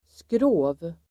Uttal: [skrå:v]